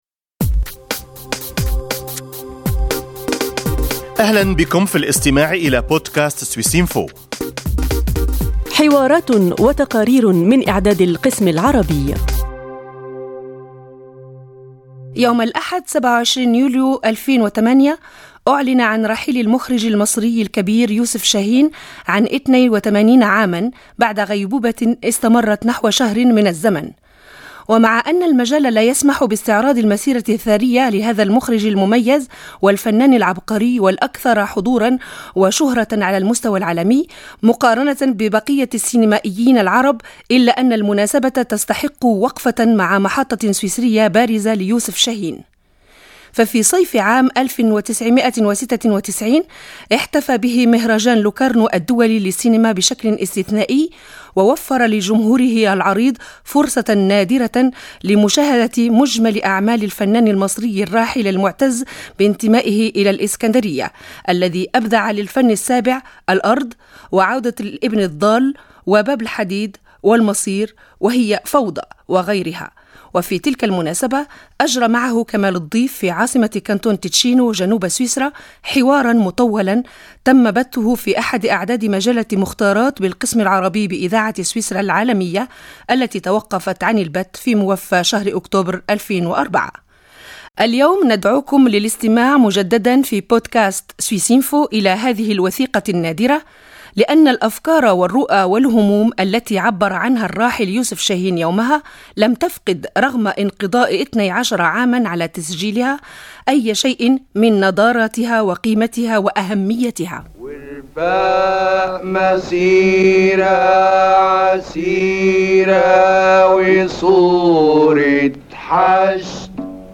في صيف 1996، كرّم مهرجان لوكارنو الدولي للسينما المخرج المصري يوسف شاهين الذي غيّـبه الموت يوم 27 يوليو 2008. وفي تلك المناسبة، أجرت معه إذاعة سويسرا العالمية حديثا مُـطولا عرض فيه باقة مهمّـة من آرائه ومواقفه التي لم تتغيّـر ولم تفقد شيئا من صوابيتها.